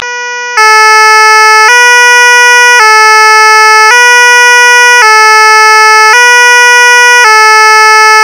Sonido francia bomberos
Sonido-francia-bomberos.wav